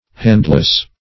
Handless \Hand"less\ (h[a^]nd"l[e^]s), a.